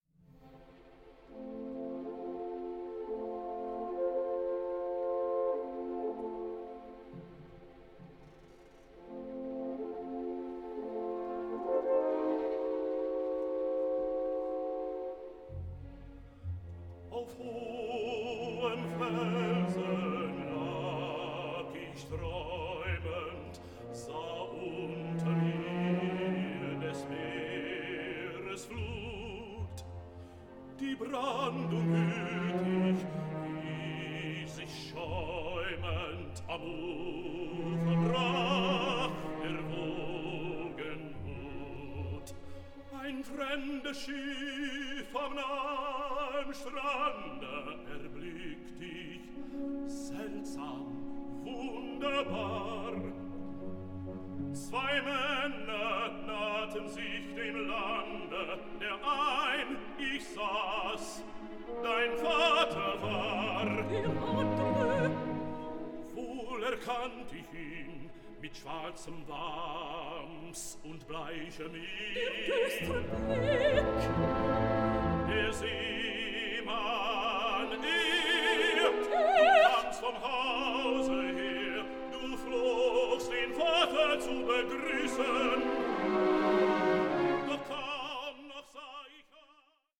5. Duett